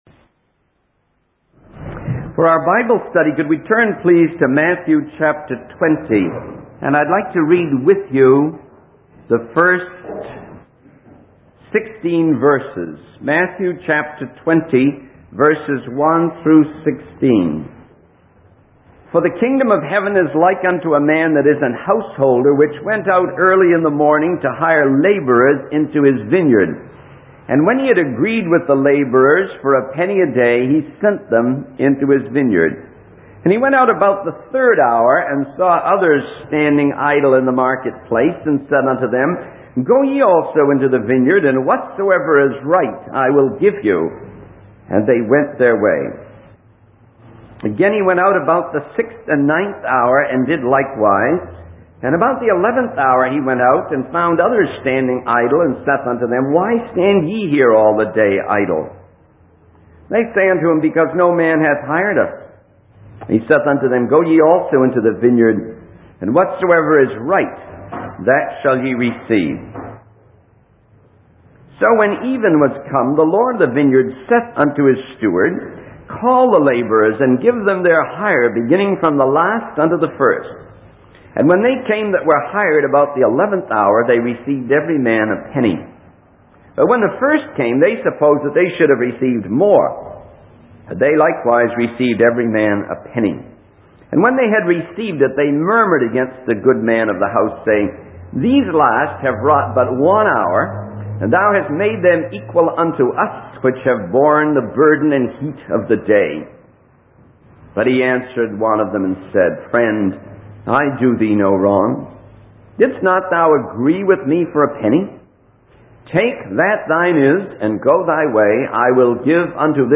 In this sermon, the speaker begins by referencing a previous interaction between Jesus and a rich man who asked about inheriting eternal life.